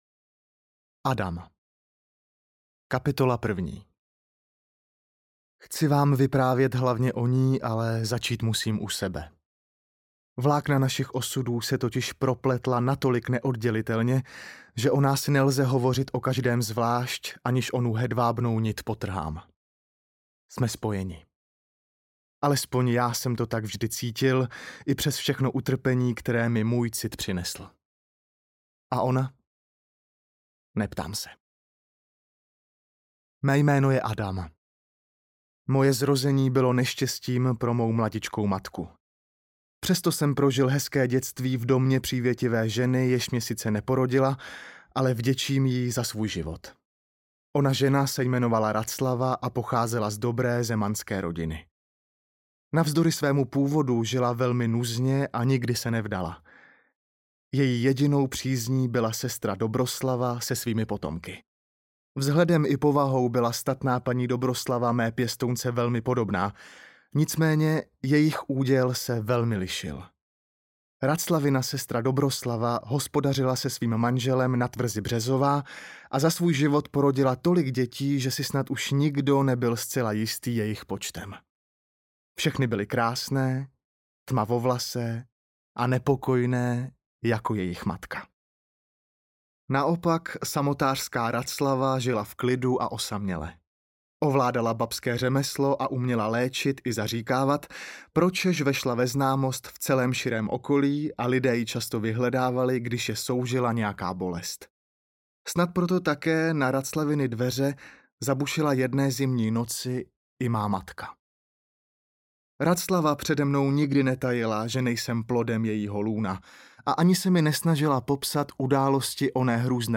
Královské intriky audiokniha
Ukázka z knihy